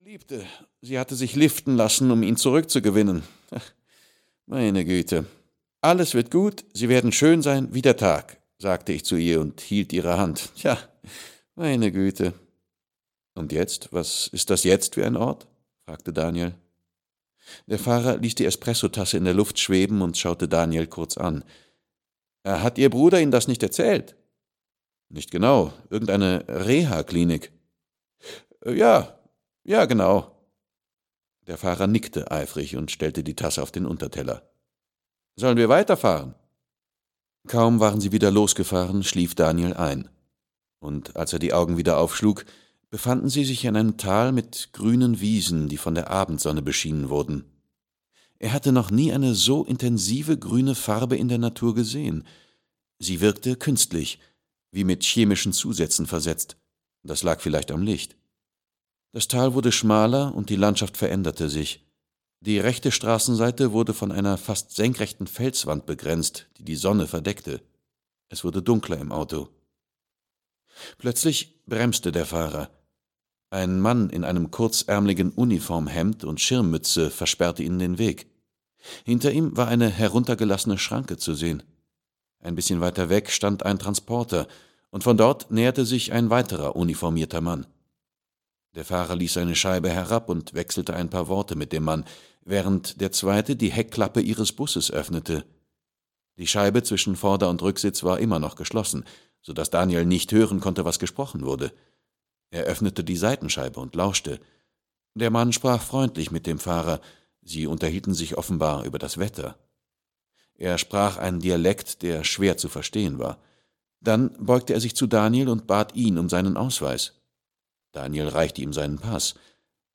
Himmelstal - Marie Hermanson - Hörbuch